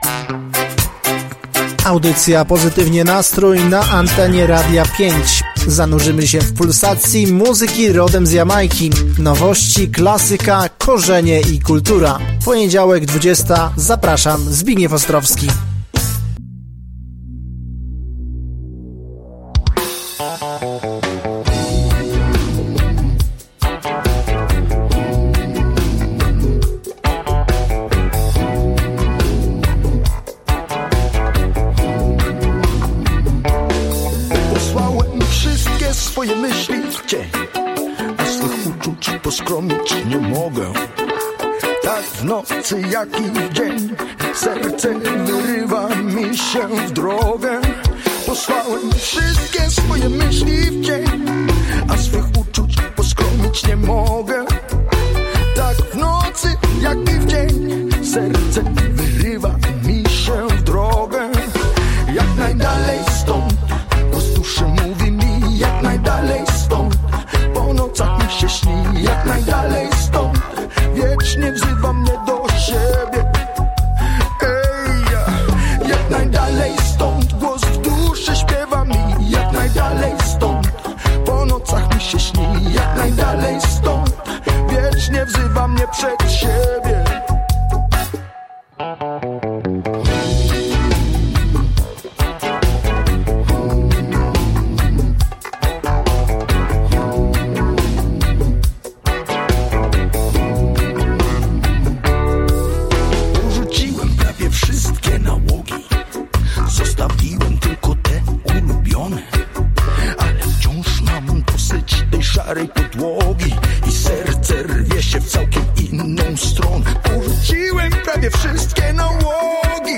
Powrót na antenie po urlopowej przerwie, z świeżym materiałem z Ostróda Reggae Festivalu.